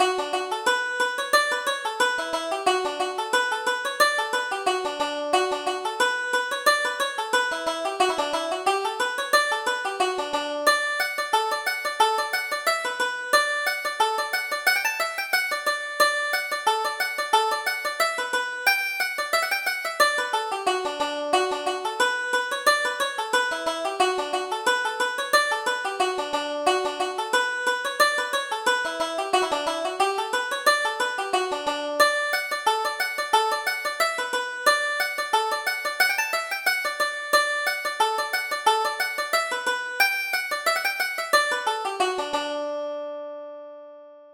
Reel:Craig's Reel